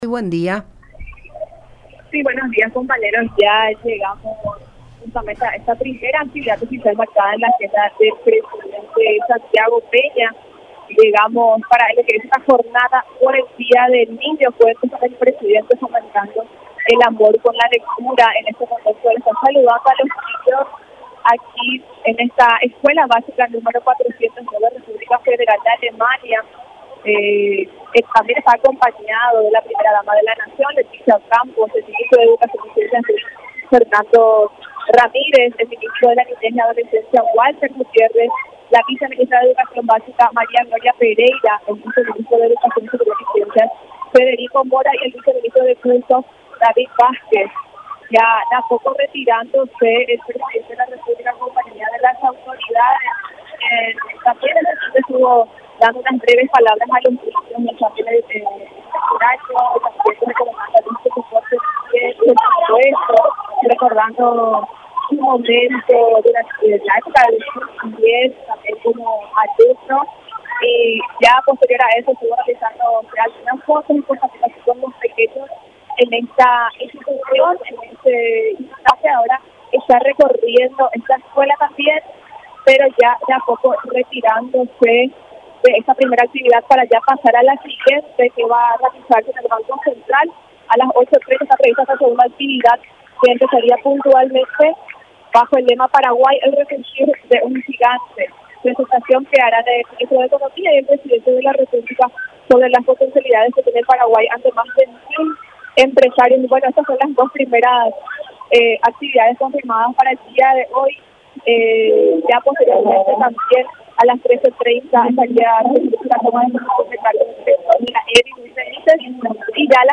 El presidente de la Administración Nacional De Electricidad, Félix Sosa habló sobre las nuevas propuestas tarifarias y adelantó que buscaran un reajuste de precio por hora, para poder beneficiar al sector productivo y al sector más vulnerable del país.